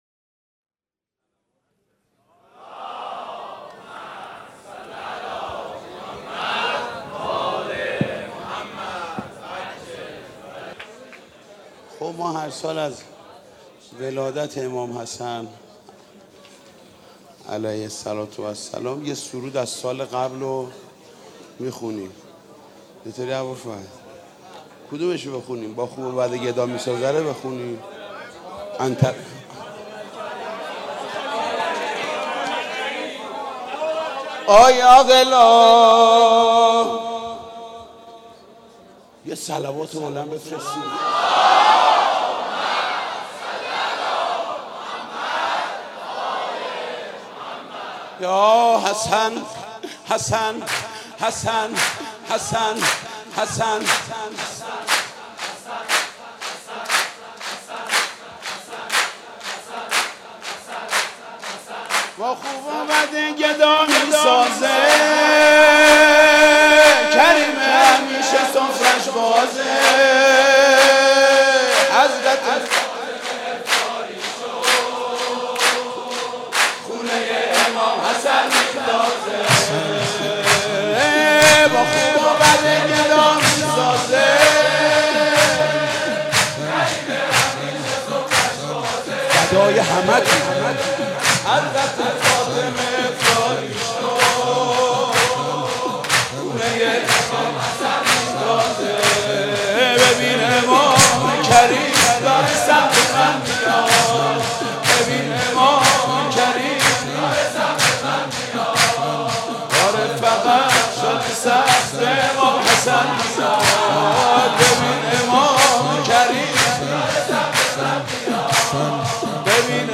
سرود ( با خوب و بد گدا می سازه